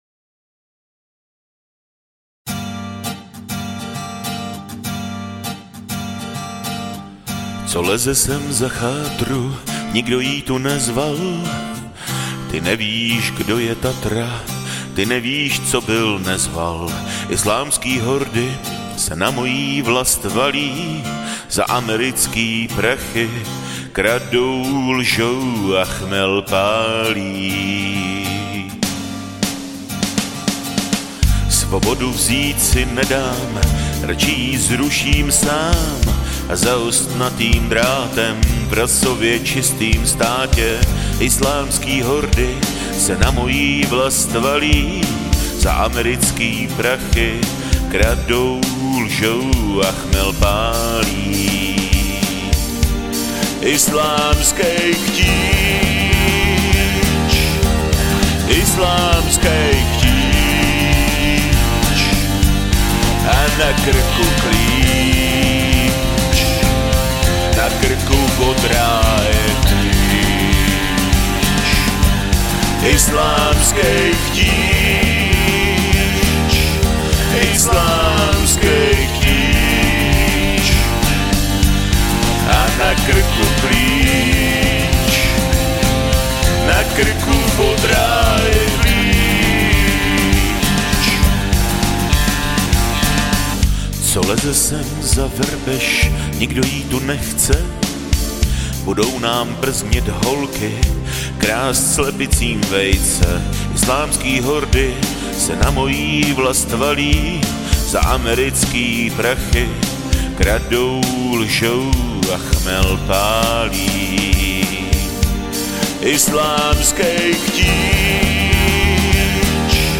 Žánr: Pop
písničkáře s notebokem.